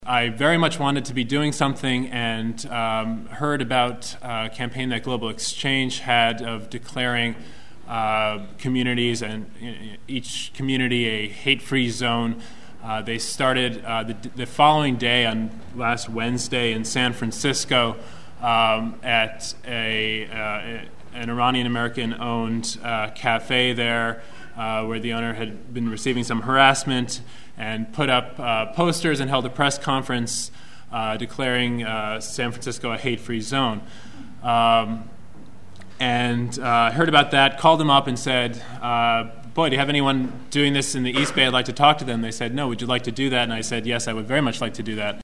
speech at the antiwar organizing meeting in Oakland 9/21/01